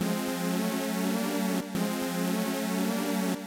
HOUSEY    -L.wav